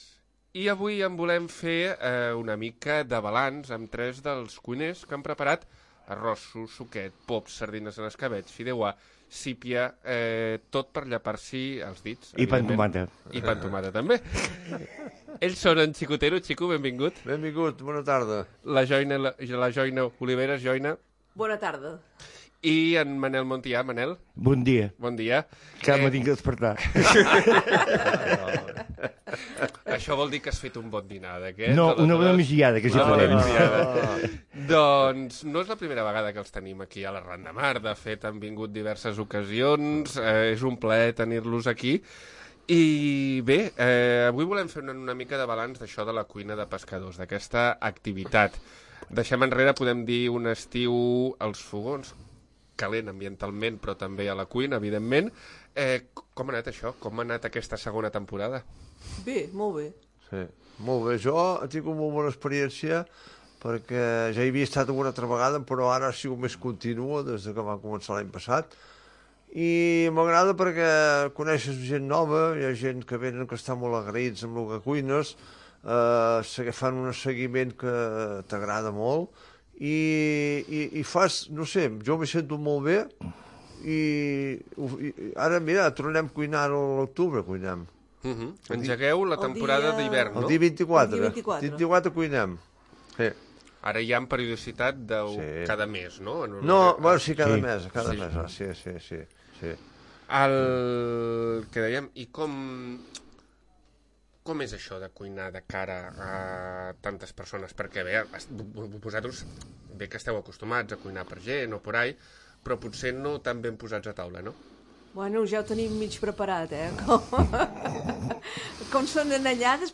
Destacats d'Arran de Mar - Tertulia de cuina de pescadors